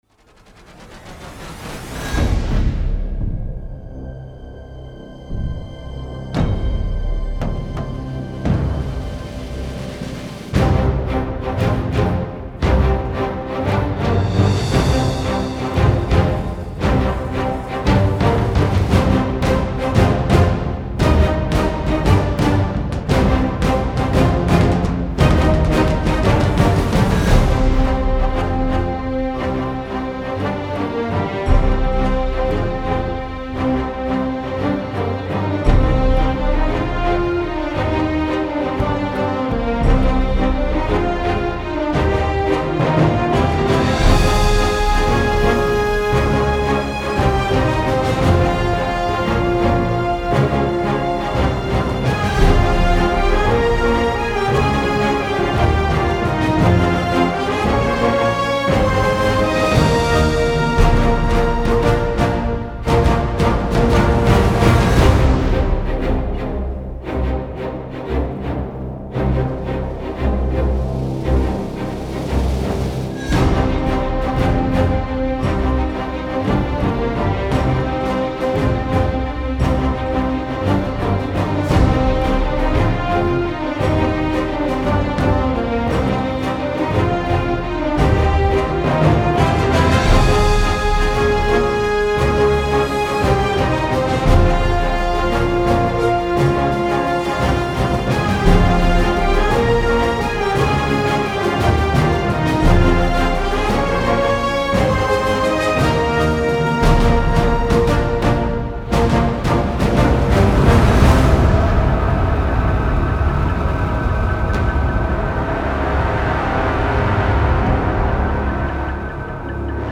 موسیقی متن موسیقی بیکلام
موسیقی حماسی